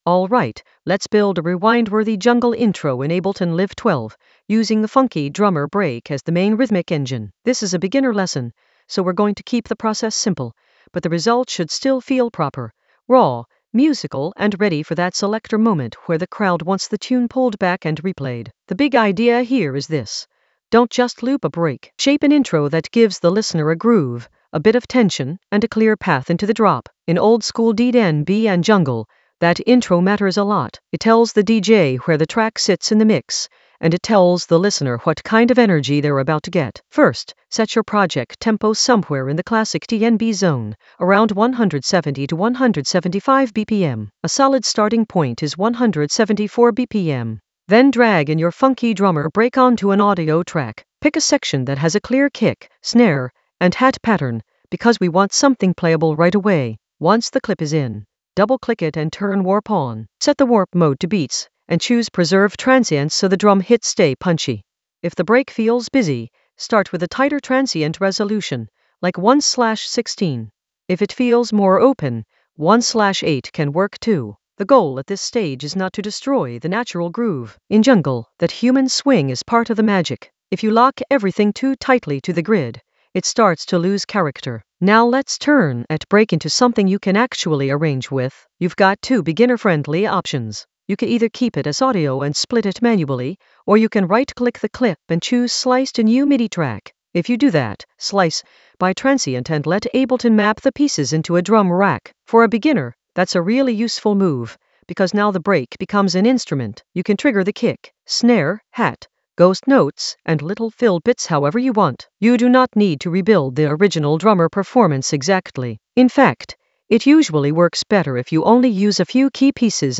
An AI-generated beginner Ableton lesson focused on Funky Drummer intro arrange guide for rewind-worthy drops in Ableton Live 12 for jungle oldskool DnB vibes in the Resampling area of drum and bass production.
Narrated lesson audio
The voice track includes the tutorial plus extra teacher commentary.